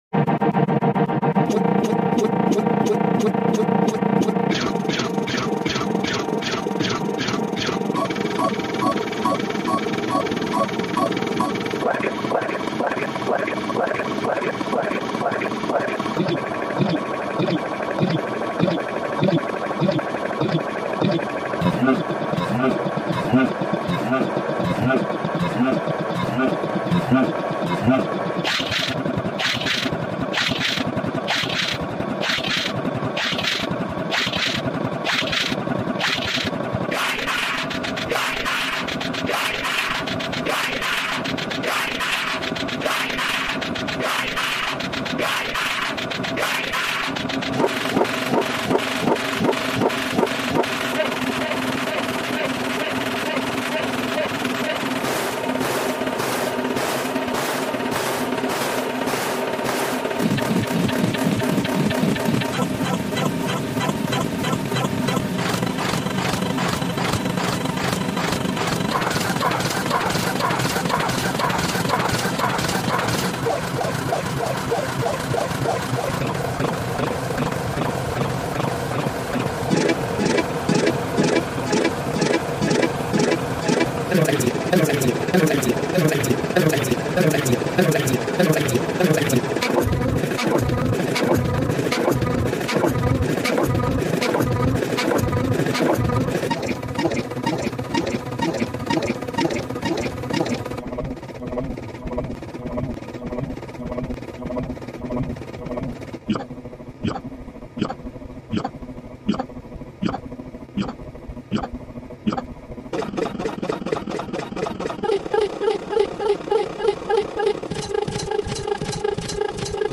Numbers 0 To Googol With Sound Full Version With 8* Speed